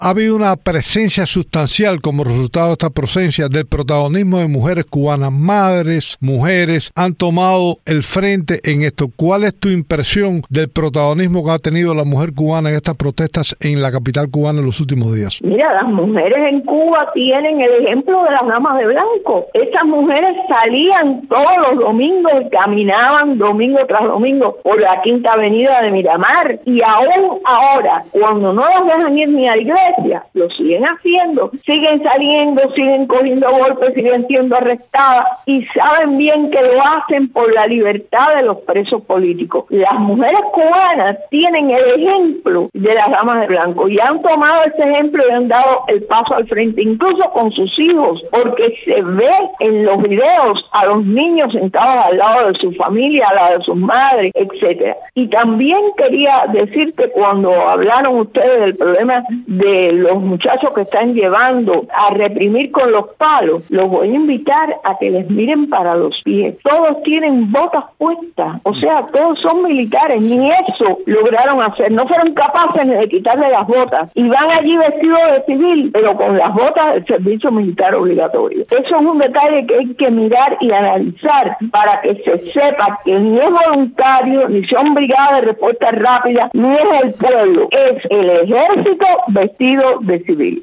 Economista cubana habla sobre aumento de presencia femenina en las protestas